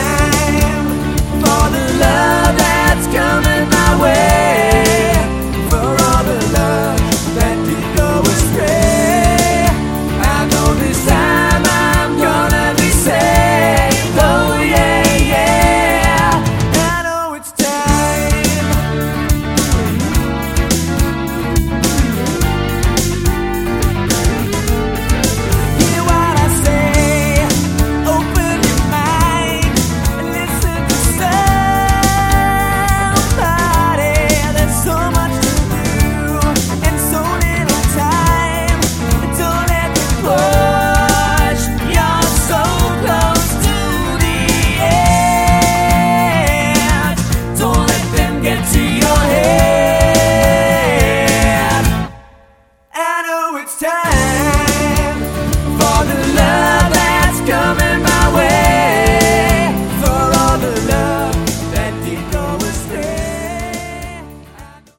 Category: AOR
vocals, guitars, keys
bass, vocals
drums, vocals